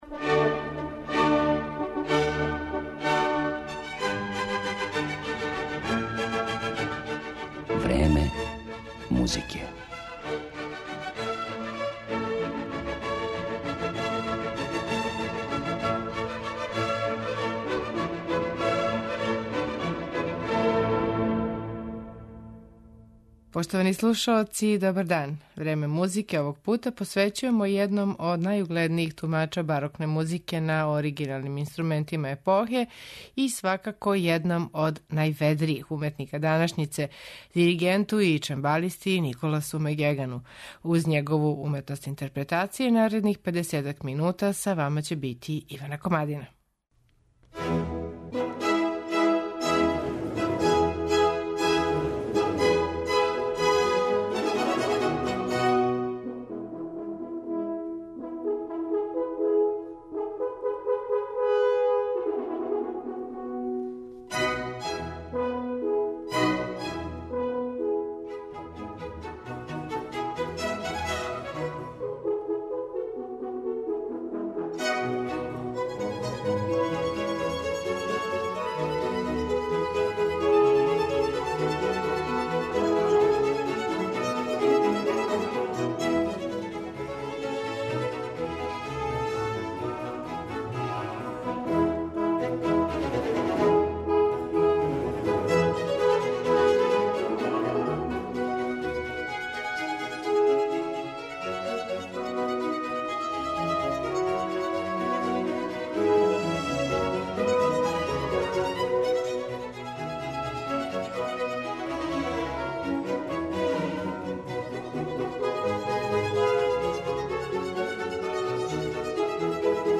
Диригент и чембалиста Николас Мек Геган
Данашње Време музике посветили смо једном од најугледнијих тумача барокне музике на оригиналним инструментима епохе и једном од најведријих уметника данашњице, диригенту и чембалисти Николасу Мек Гегану.